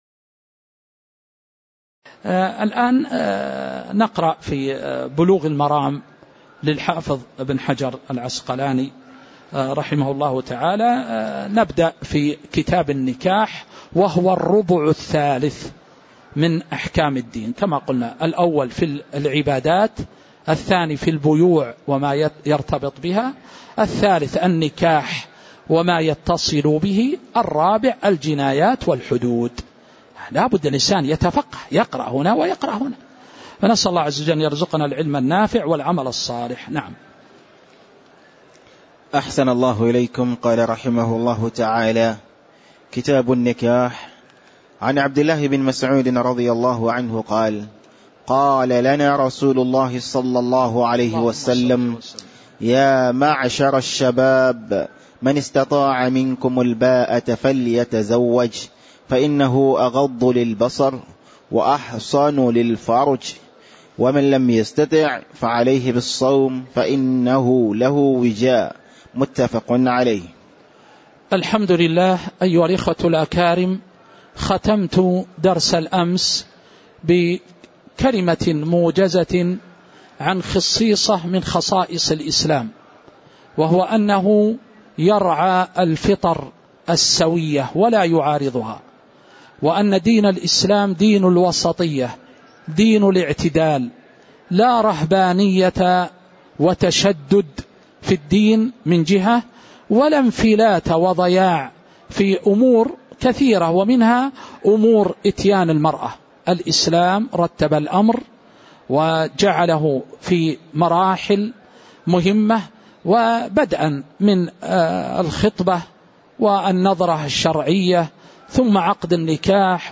تاريخ النشر ٣ شعبان ١٤٤٦ هـ المكان: المسجد النبوي الشيخ